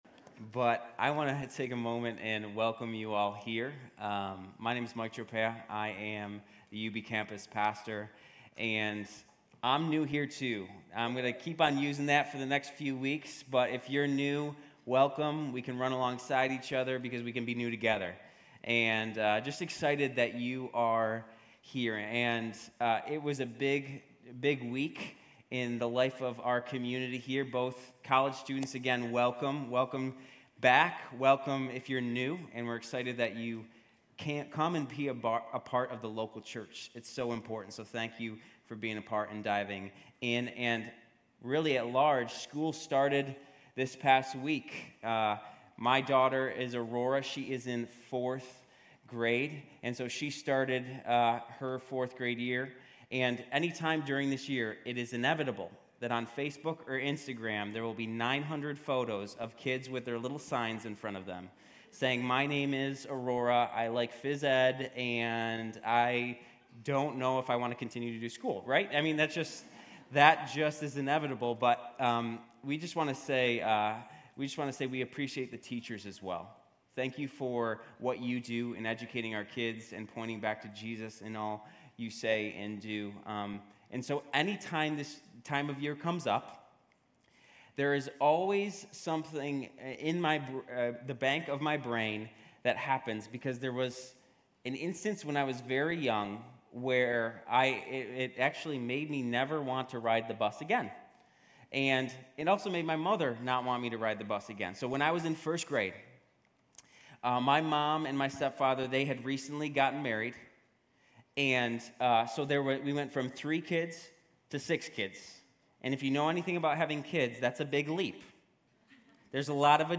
Grace Community Church University Blvd Campus Sermons James 5:13-20 Aug 20 2023 | 00:31:25 1x 00:00 / 00:31:25 Subscribe Share RSS Feed Share Link Embed